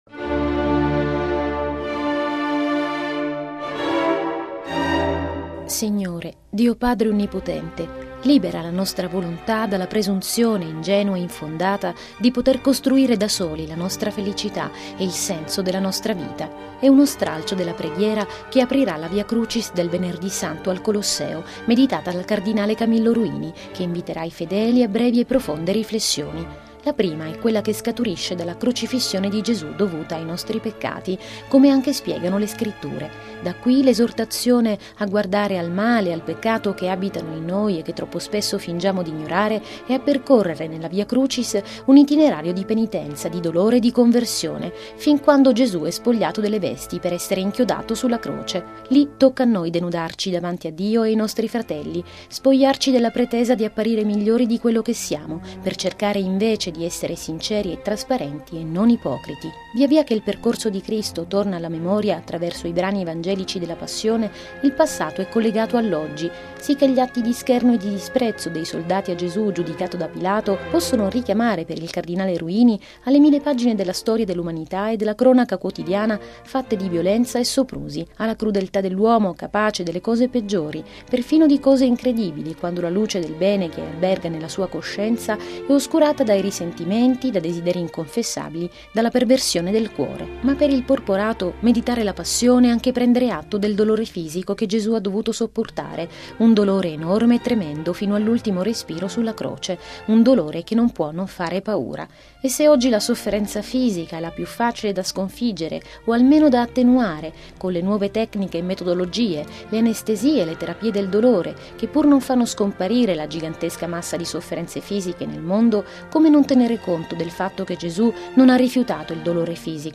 (musica)